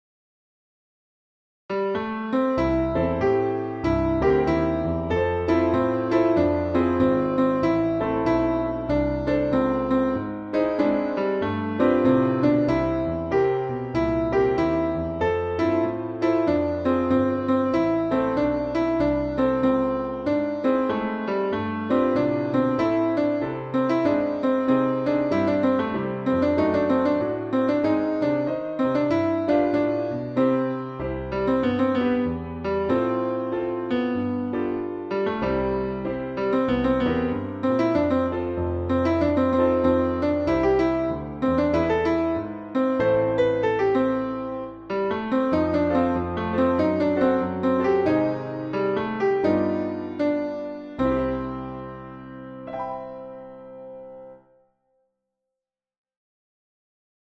BCA Fall Play, Pericles Piano Accompaniment, Vocal
Having gained her experience in a 1930's brothel, the piece captures that era with a smoky, smooth, and simple piano accompaniment. The track is deliberately understated—flattering Marina's voice without extravagance.
if-its-alright-accompainment.custom_score.mp3